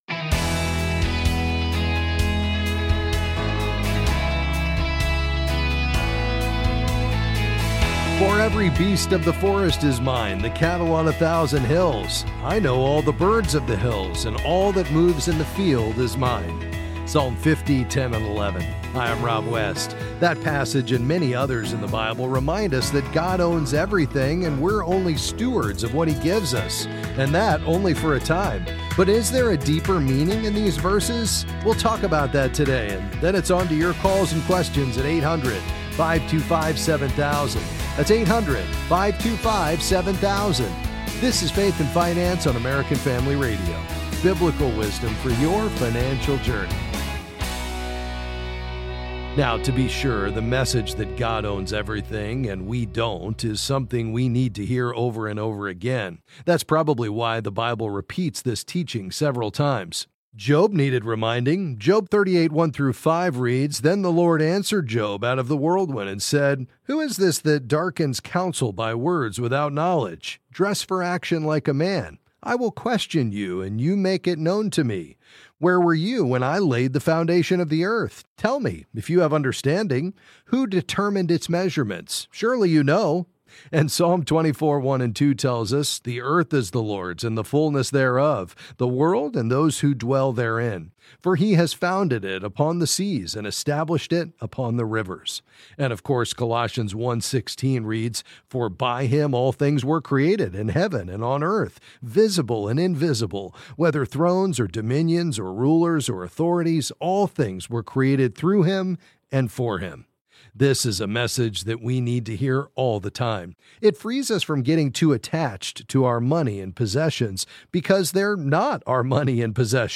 Then he answers questions on various financial topics.